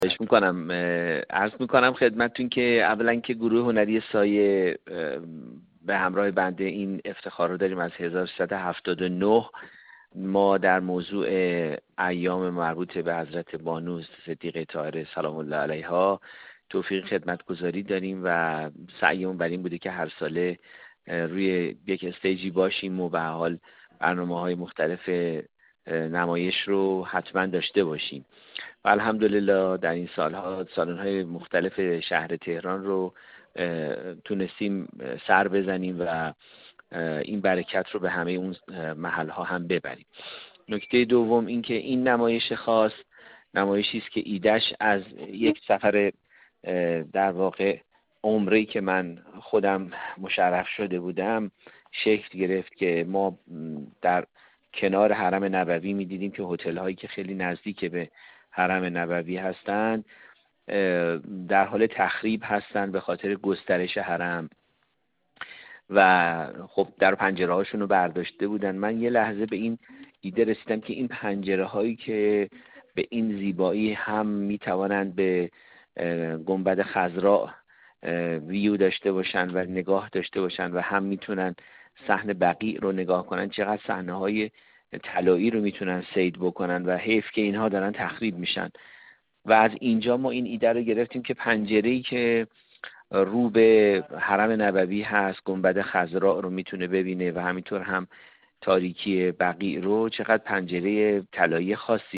گفت‌و‌گو